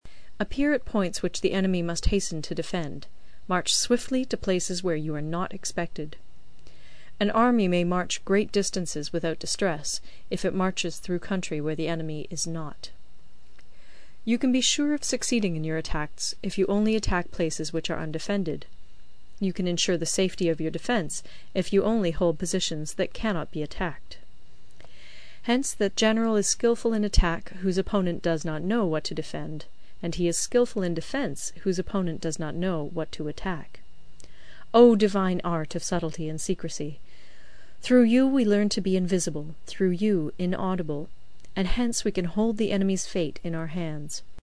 有声读物《孙子兵法》第33期:第六章 虚实(2) 听力文件下载—在线英语听力室